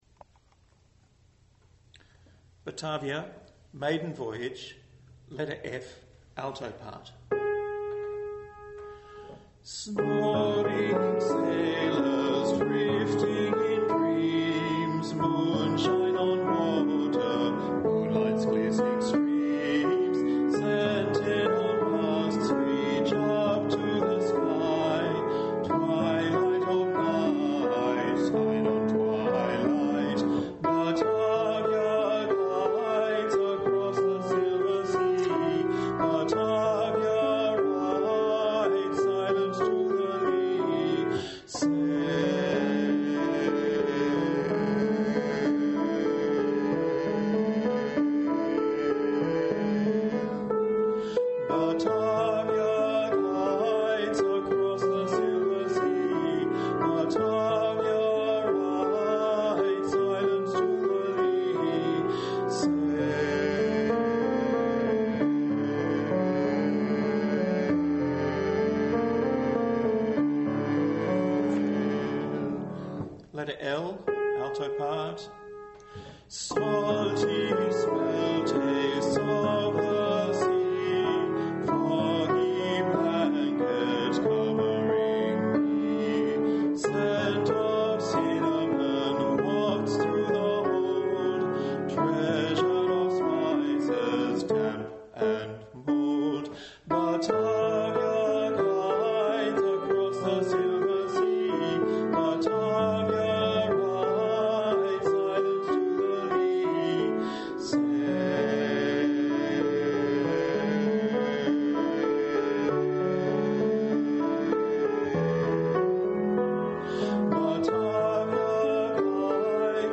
16a LEEK Batavia MaidenVoyage ALTO